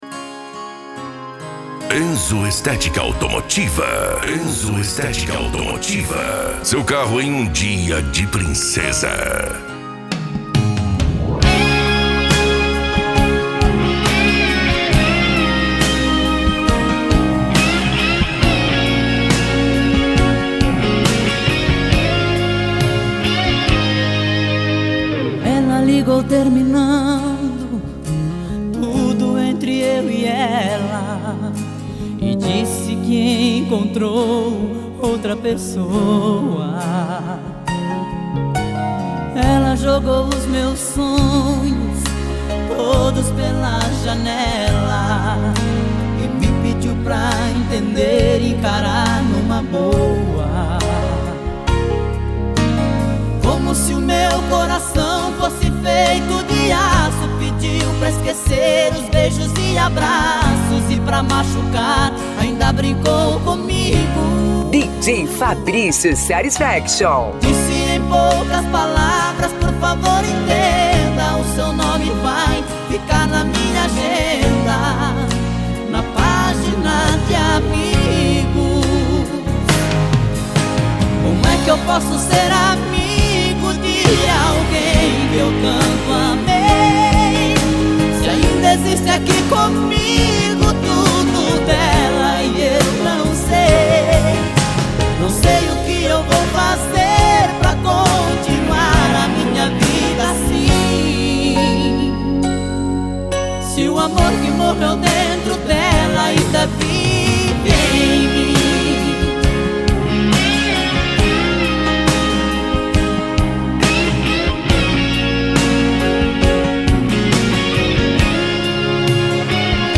SERTANEJO
Sertanejo Raiz